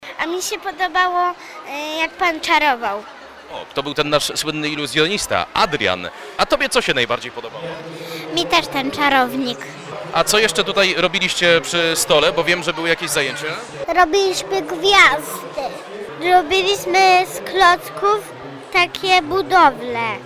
Dobra energia i dobra zabawa królują dziś w Parku Słowackiego w Bielsku-Białej. To 13. odsłona Beskidzkiego Festiwalu Dobrej Energii.